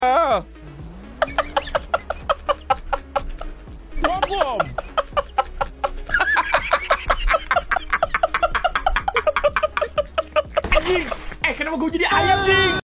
mirip suara ayam🤣